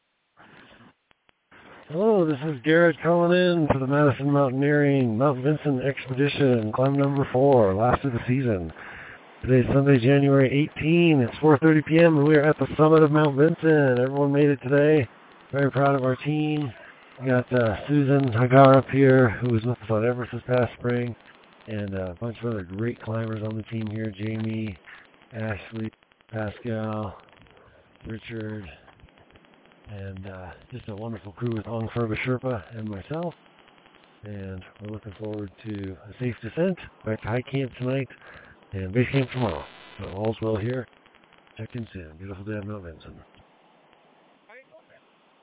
checks in with this dispatch from the summit of Mount Vinson: